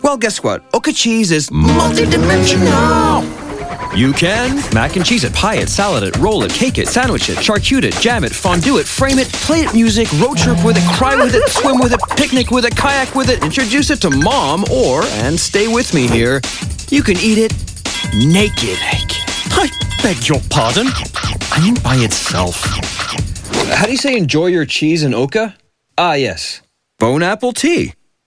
Publicité (OKA) - ANG